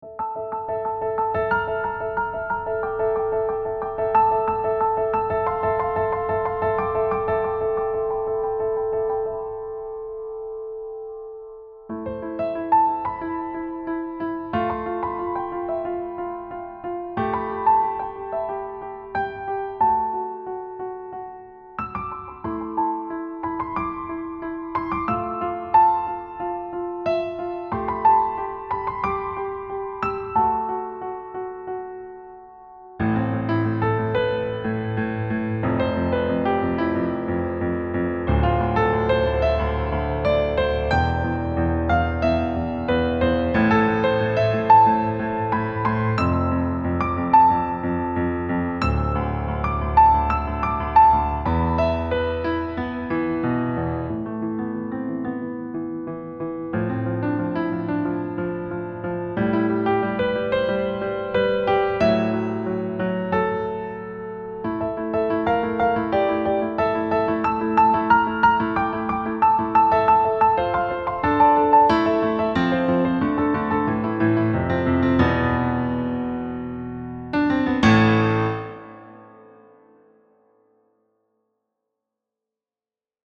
• Key: A Natural Minor (with occasional accidentals)
• Time signature: 4/4